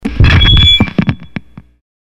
Звуки лагающего микрофона